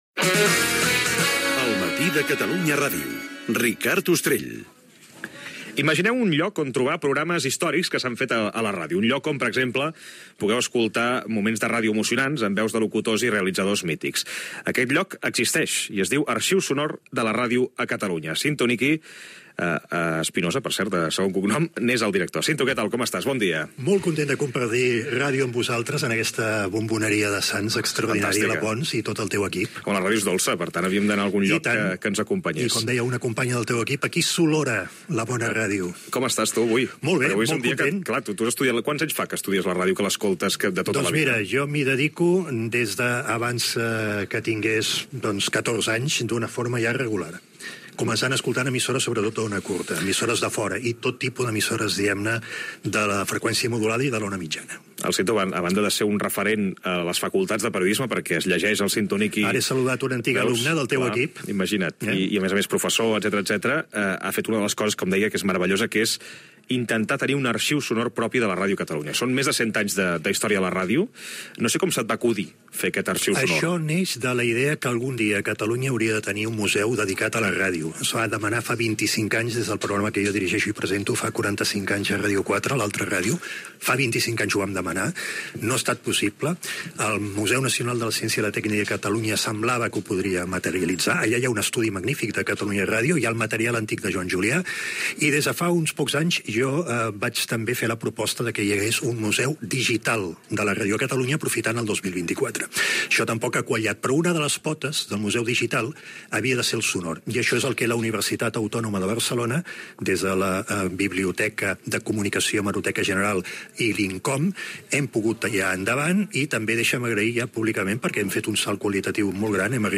Gènere radiofònic Info-entreteniment
Programa fet el Dia Mundial de la Ràdio de l'any 2025 a la Bomboneria Pons del barri de Sants de Barcelona.